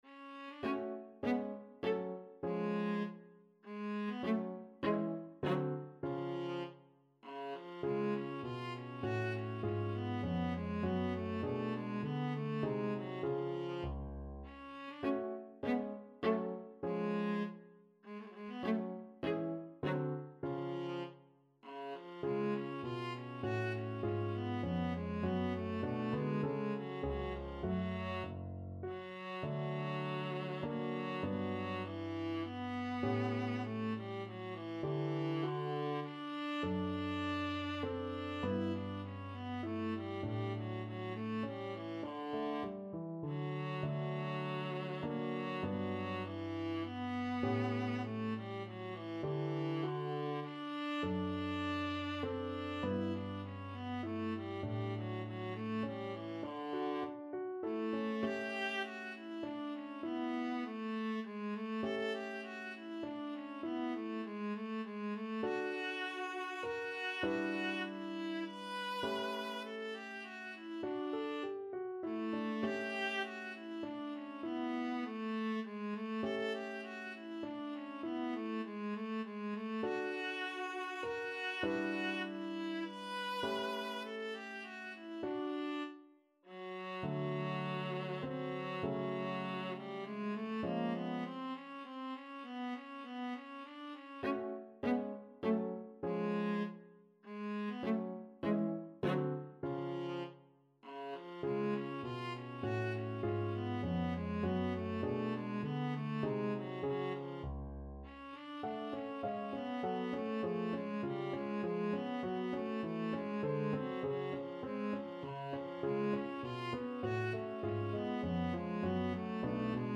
Allegretto
3/4 (View more 3/4 Music)
Classical (View more Classical Viola Music)